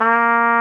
Index of /90_sSampleCDs/Roland LCDP12 Solo Brass/BRS_Trumpet 5-7/BRS_Tp 7 Warm